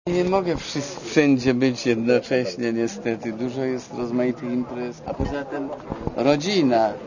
Pojawił się natomiast w czwartek w Mistrzowskiej Szkole Reżyserii Filmowej Andrzeja Wajdy, gdzie na pytanie o nieobecność na rozdaniu Orłów Polański odpowiedział: